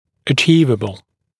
[ə’ʧiːvəbəl][э’чи:вэбэл]достижимый